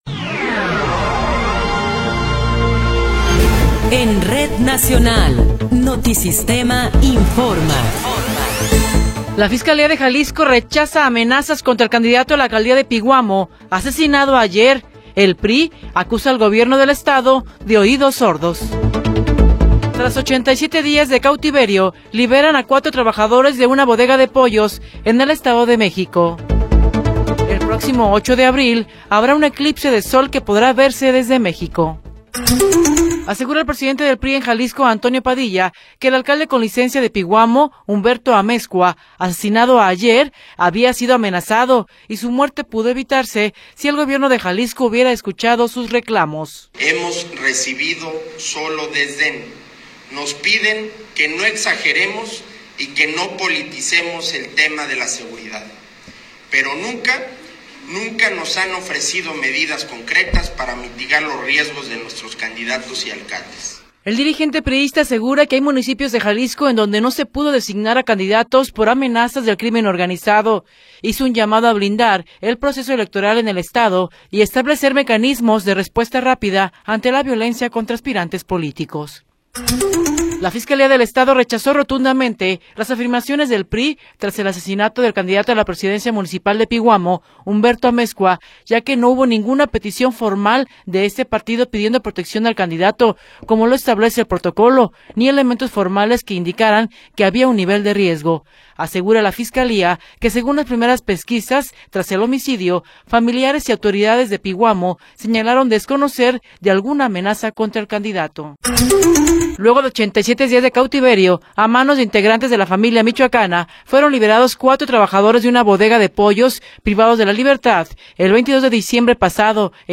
Noticiero 20 hrs. – 16 de Marzo de 2024